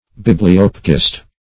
Search Result for " bibliopegist" : The Collaborative International Dictionary of English v.0.48: Bibliopegist \Bib`li*op"e*gist\ (b[i^]b`l[i^]*[o^]p"[-e]*j[i^]st), n. A bookbinder.
bibliopegist.mp3